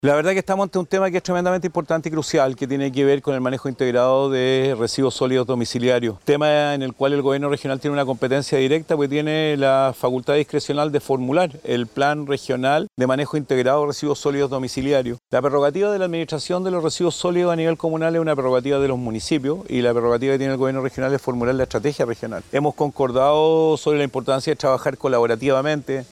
En este escenario, Rodrigo Mundaca, gobernador Regional de Valparaíso, confirmó que formularán un plan regional de manejo integrado de los residuos sólidos domiciliarios para generar un trabajo colaborativo y enfrentar la problemática.
cu-reunion-el-molle-mundaca-2.mp3